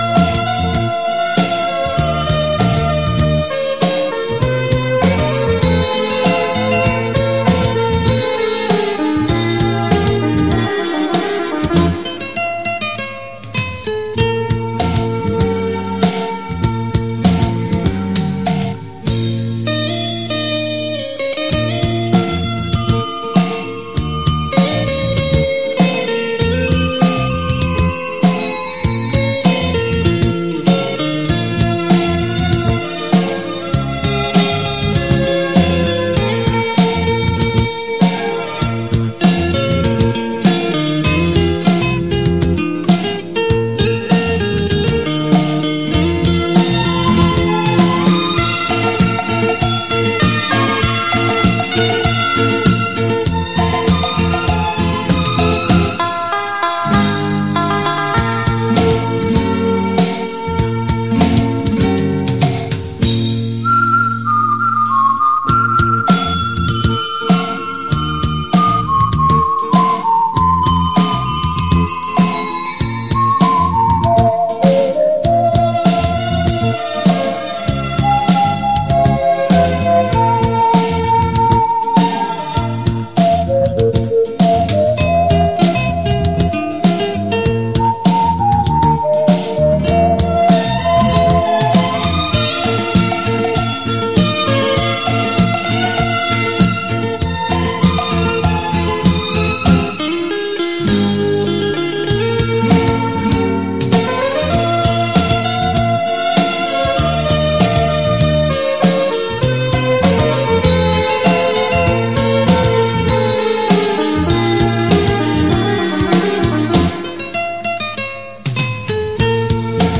雷射声立体Hi-Fi
清新明快 曲调优美
磁带数字化